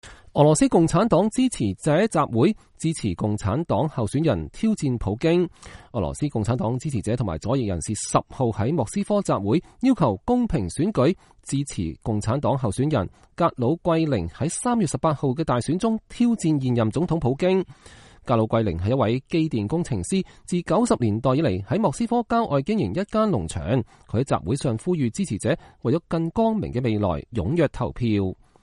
他在集會上呼籲支持者“為了更光明的未來”踴躍投票。